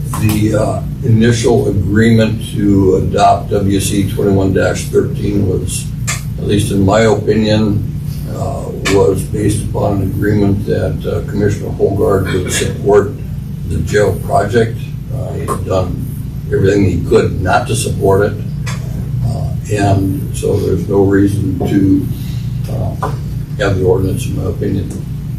Commissioner Rick Cain said it was his opinion that there is no longer a need for the ordinance.